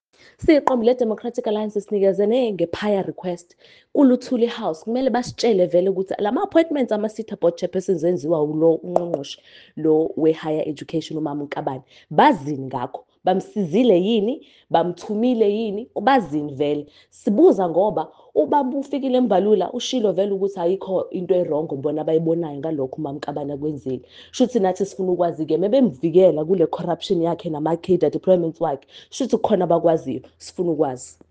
isiZulu soundbites by Karabo Khakhau MP.
Karabo-isiZulu-soundbite.mp3